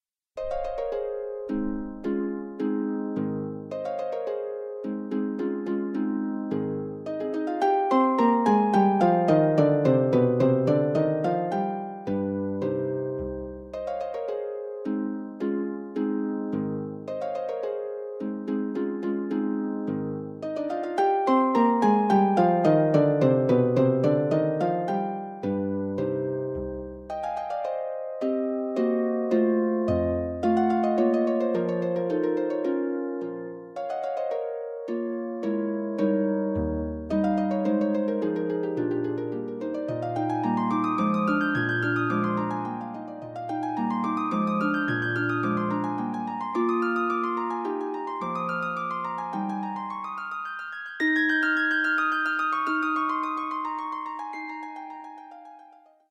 for solo pedal harp